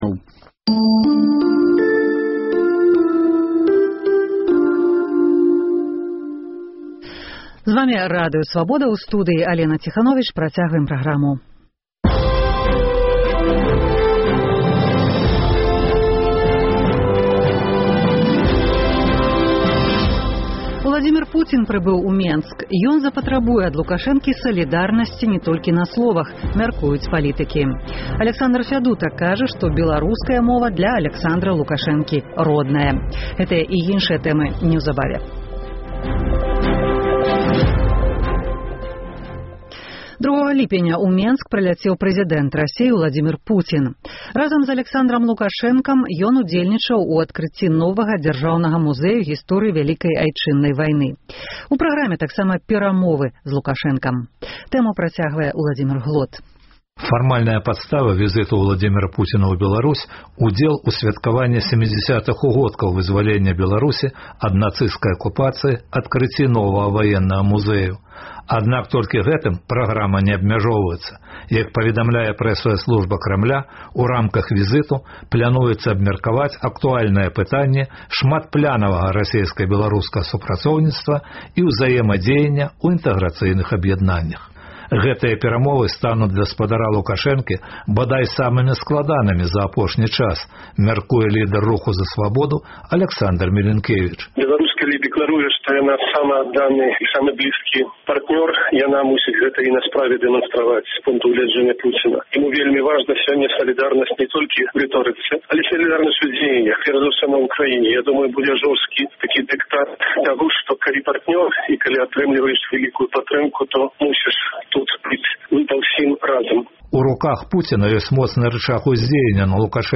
Тэлефануйце ў жывы эфір: +